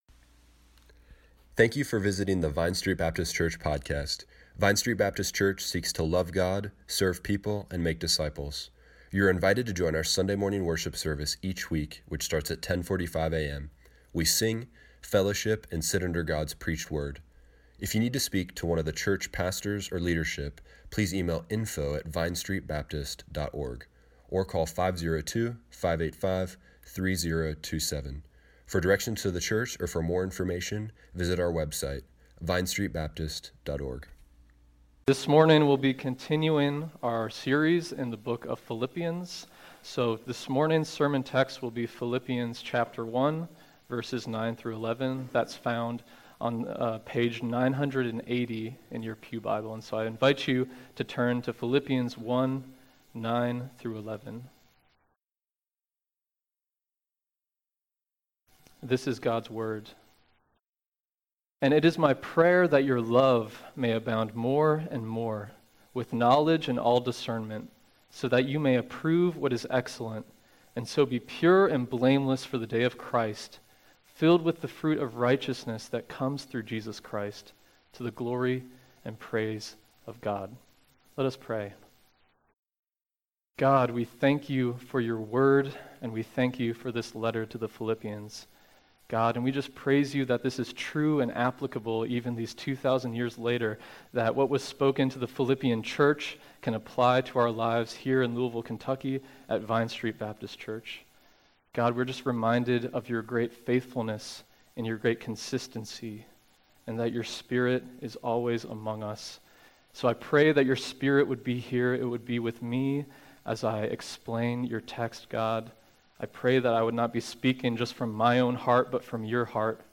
Service Morning Worship
2019 Results in Abounding love Rooting in Christ Knowledge Praise to God through us Click here to listen to the sermon online.